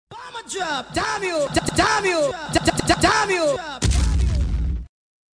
Boom A Drop Damm You Voice Jingle Sampler
HD Boom A Drop Damm You Jingle Sampler. For Djs